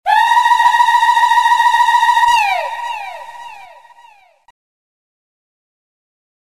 שלום רב יש לי שאלה אין לי גישה לפורום מוסיקה רציתי לשאול האם במקרה אולי למישהו כאן יש סאונד של יללות מהמזרח התיכון כמו שעושים בחתונות למשל את ה .
קולולו.wav אתה שומע?